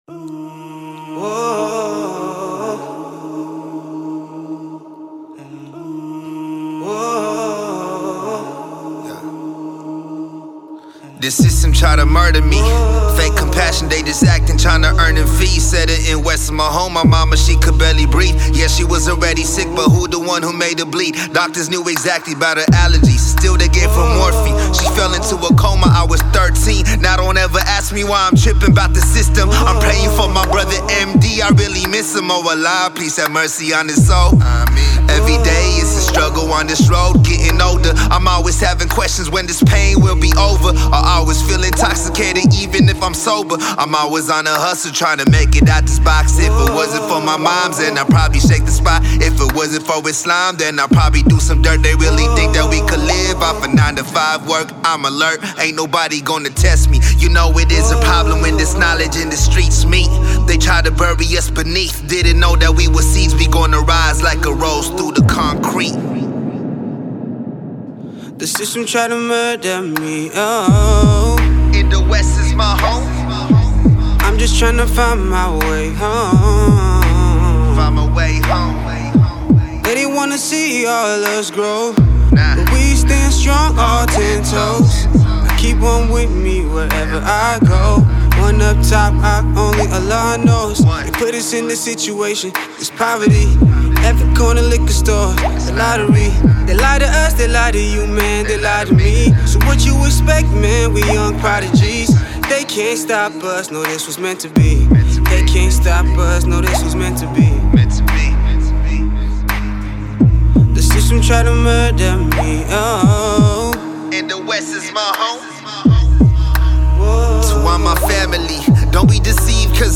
rapper/poet
stunning vocals